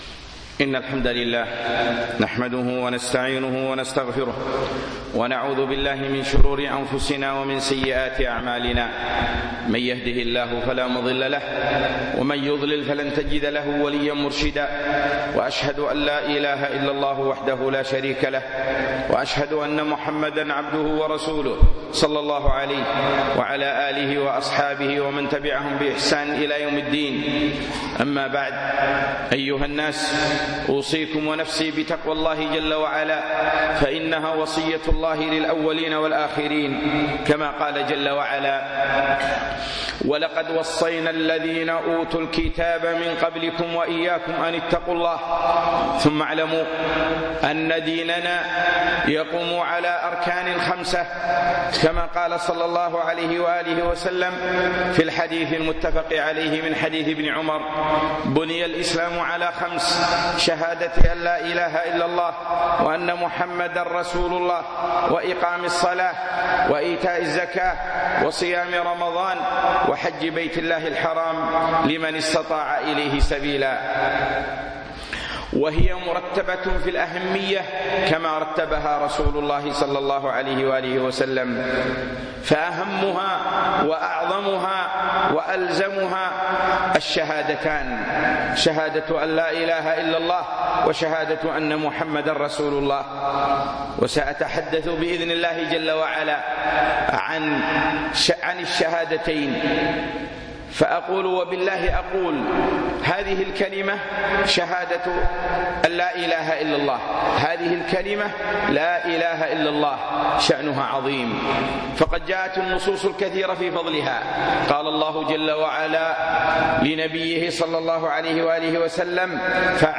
أركان الإسلام - خطبة